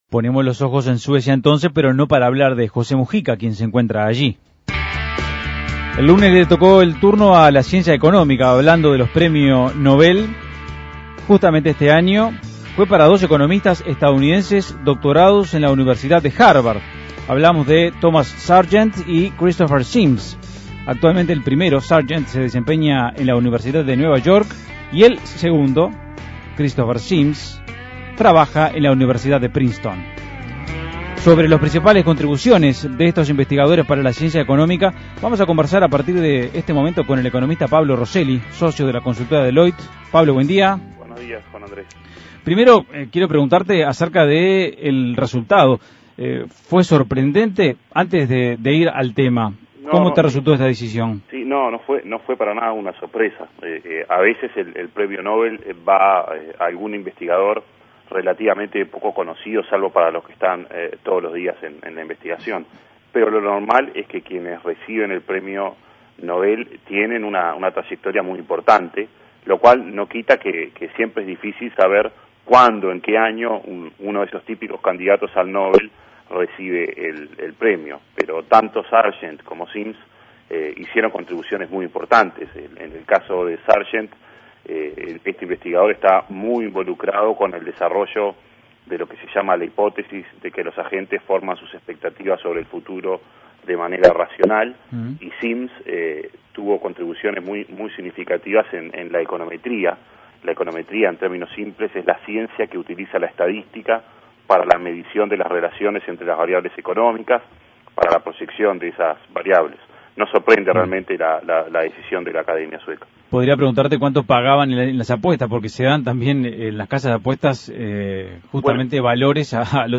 Análisis Económico El Premio Nobel de Economía en 2011 fue para Thomas Sargent y Christopher Sims: ¿cuáles fueron las principales contribuciones de esos investigadores?